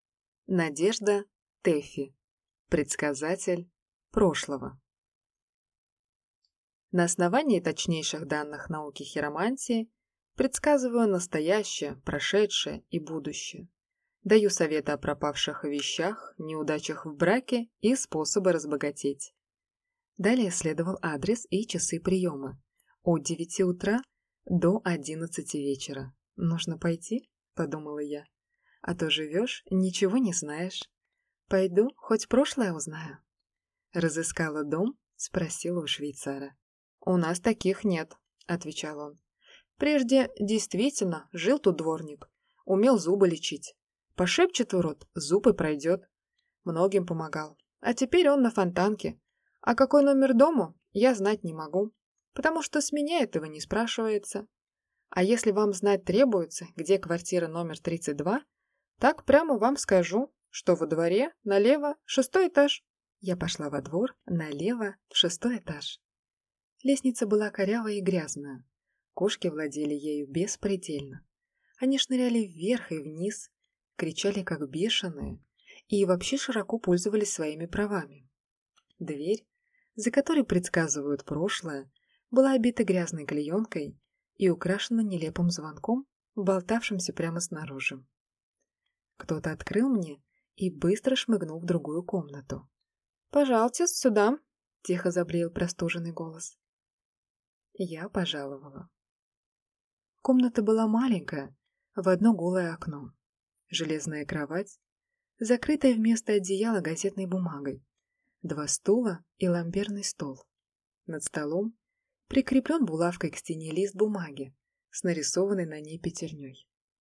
Аудиокнига Предсказатель прошлого | Библиотека аудиокниг
Прослушать и бесплатно скачать фрагмент аудиокниги